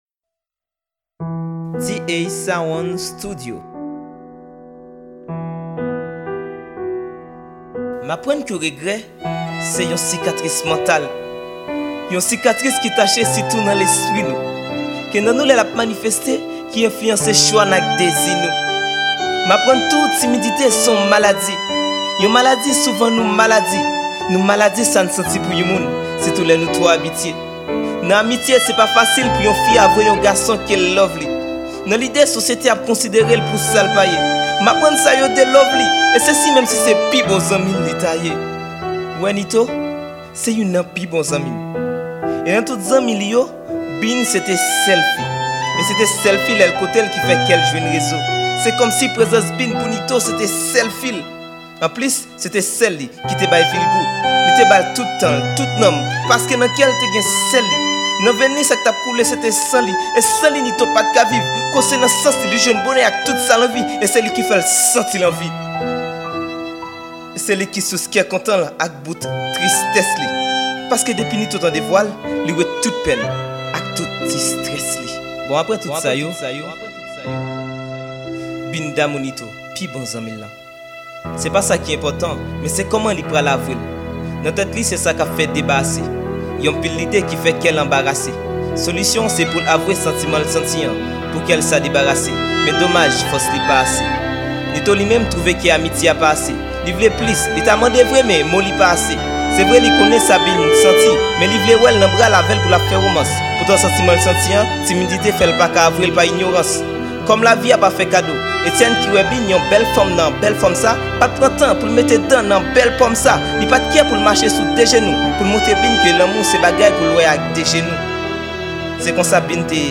Genre: SLAM.